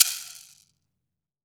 WOOD SHAKER8.WAV